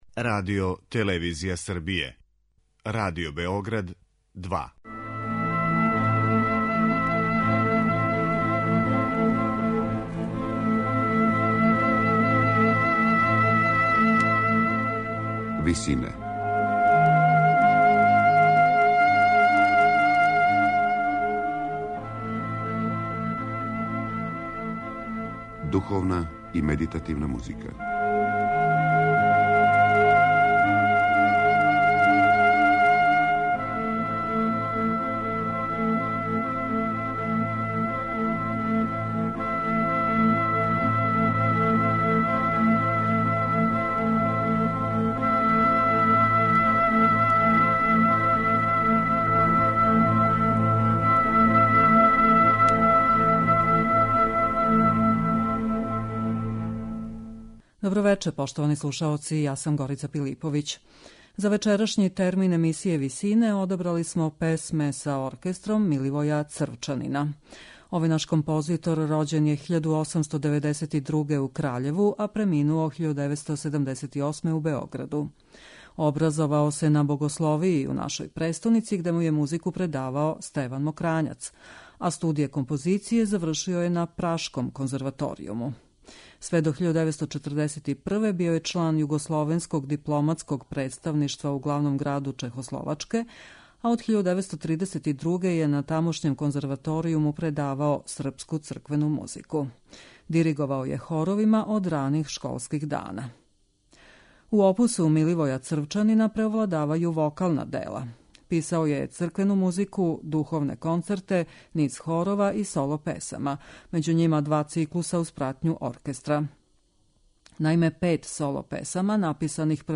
Вокална дела Миливоја Црвчанина
На крају програма, у ВИСИНАМА представљамо медитативне и духовне композиције аутора свих конфесија и епоха.
уз пратњу оркестра